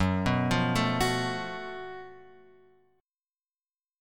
F# Minor Major 7th Double Flat 5th